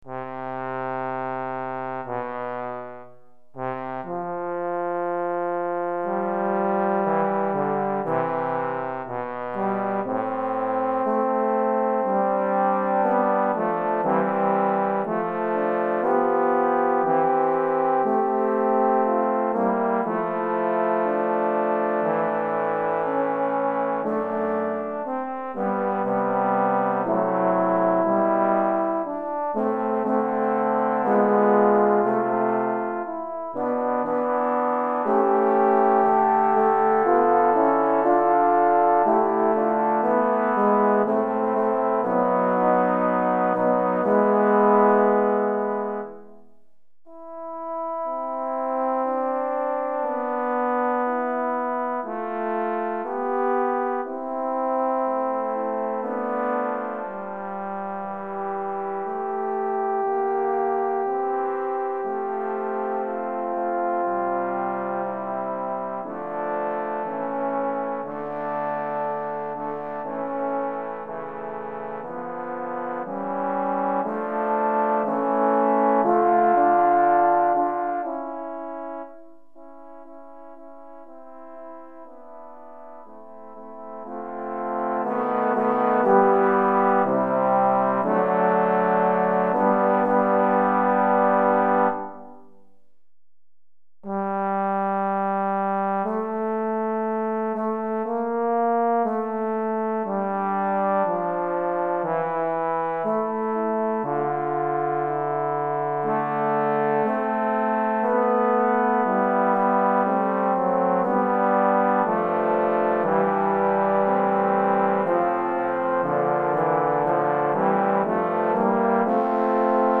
3 Trombones et Trombone Basse